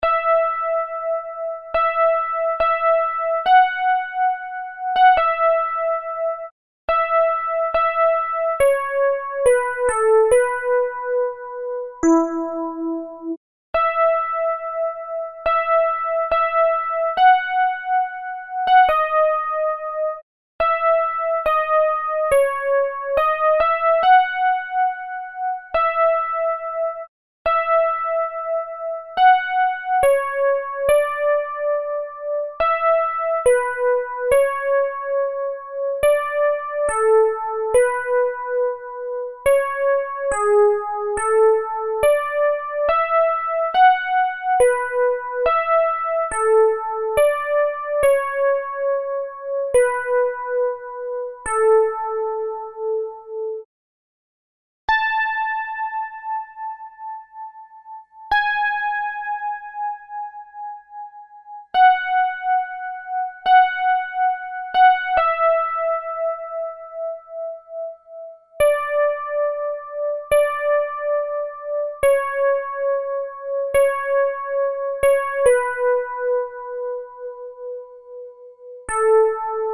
Soprani
brahma_soprani.MP3